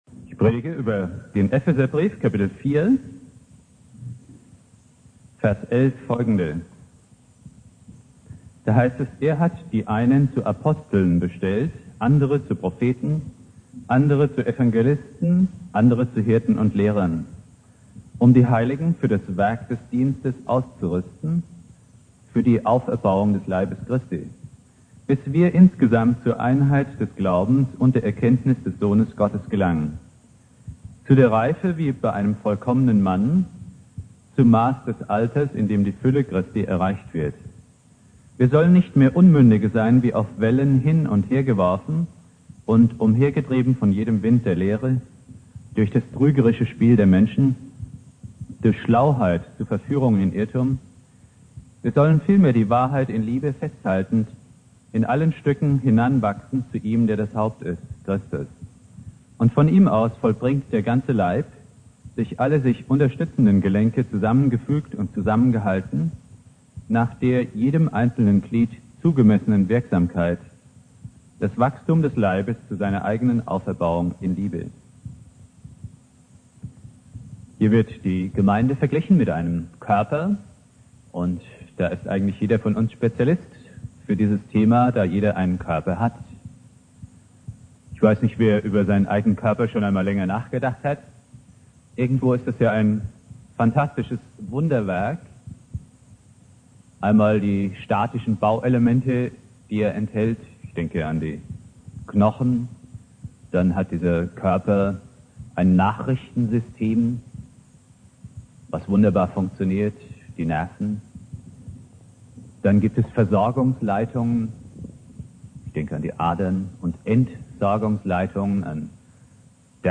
Predigt
Pfingstmontag